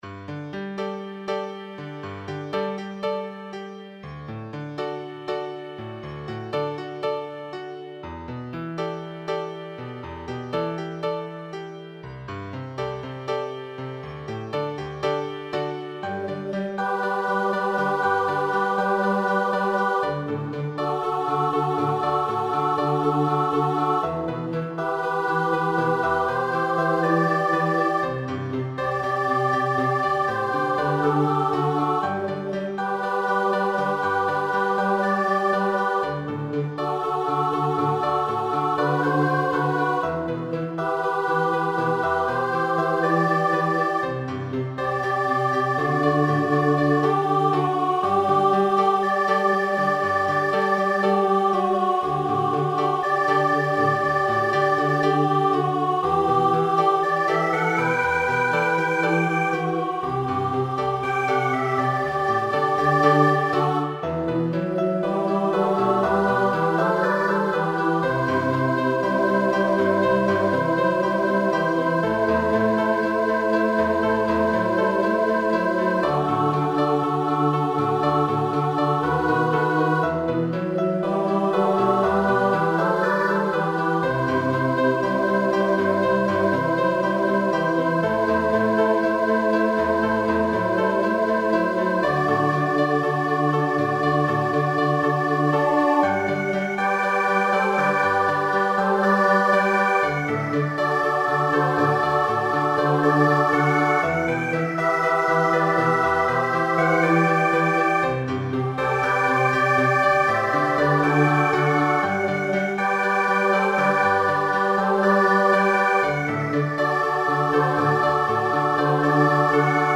fun and energetic
mixed adult voice choir and piano